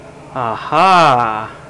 Ah Ha Sound Effect
Download a high-quality ah ha sound effect.
ah-ha-1.mp3